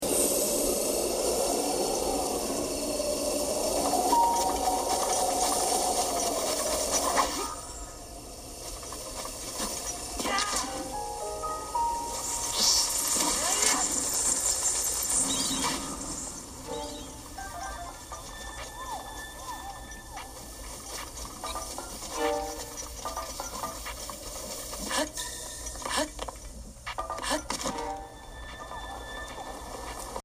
Field Recording
Me playing Zelda: Breath of the Wild, and some of the sound effects in game.
Botw-Field-Recording.mp3